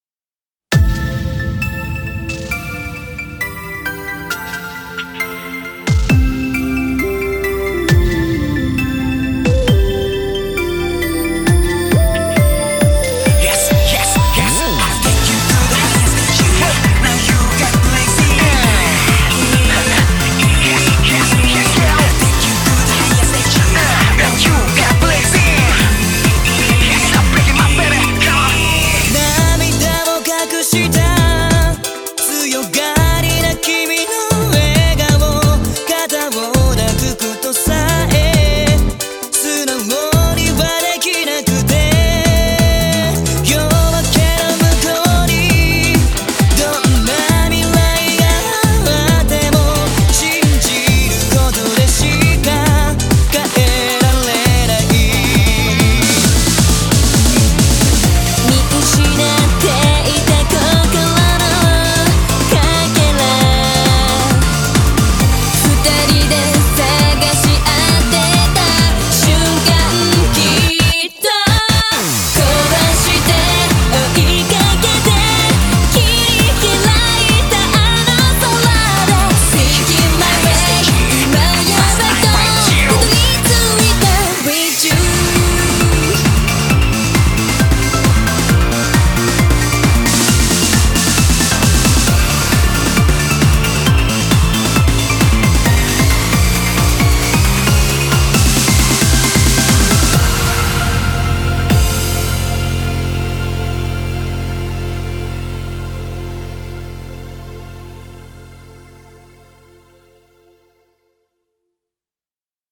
BPM134-134